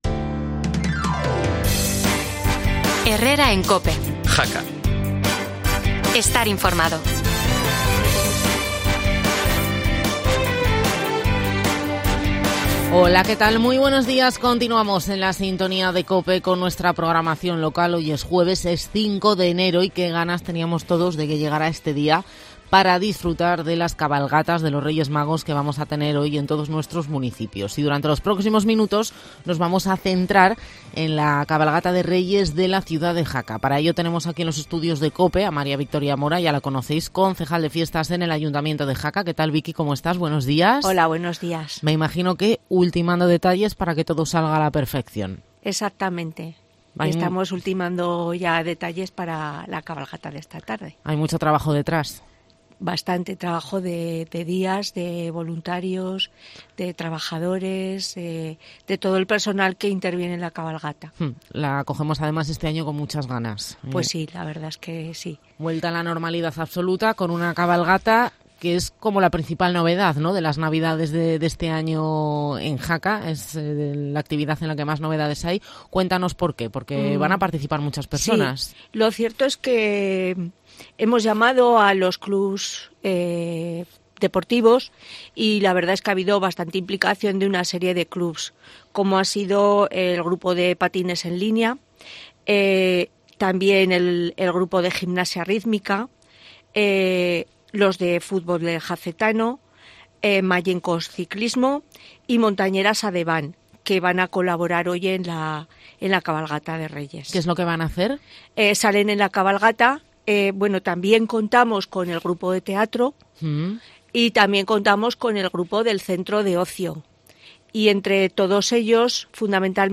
La concejal de Fiestas, Mª Victoria Mora, explica en COPE los detalles de la cabalgata y su recorrido
Escucha a la concdejal, Mª Victoria Mora en COPE